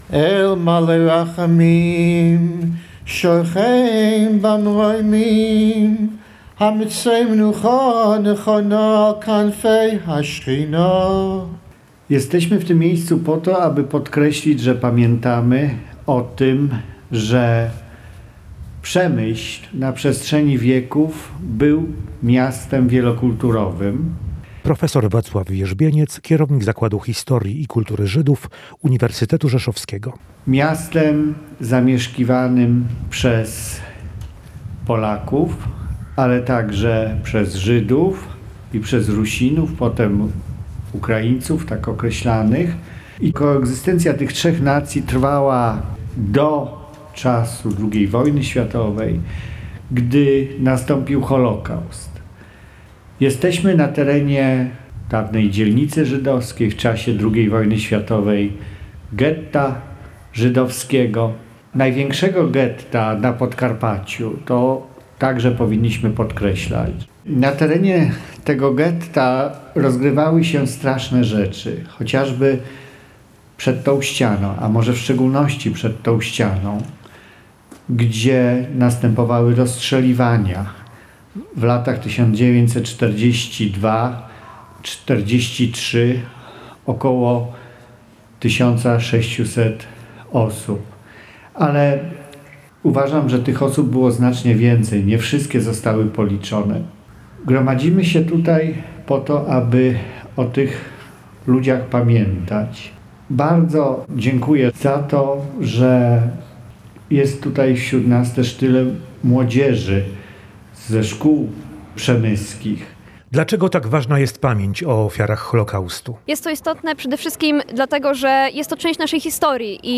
W Przemyślu pod ścianą straceń dawnego żydowskiego getta, odbyły się uroczystości Międzynarodowego Dnia Pamięci o Ofiarach Holokaustu.